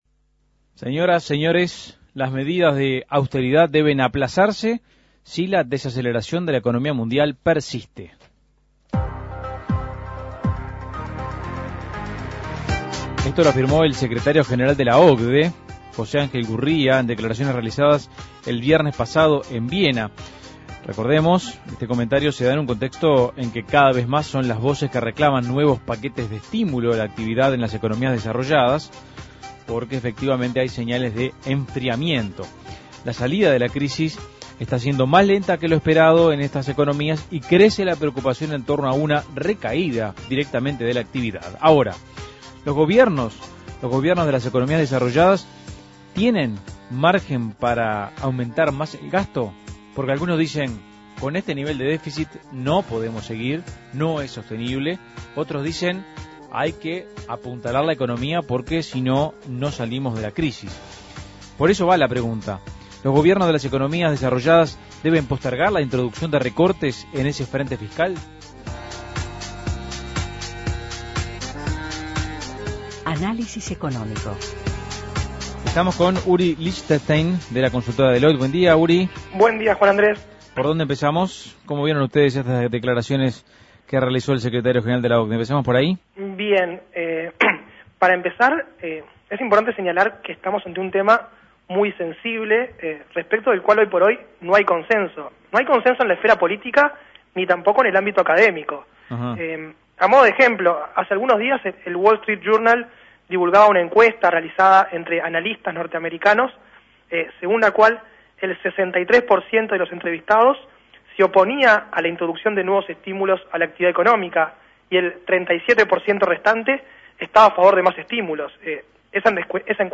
Análisis Económico Las economías desarrolladas se desaceleran: ¿deben los gobiernos adoptar nuevas medidas de estímulo fiscal?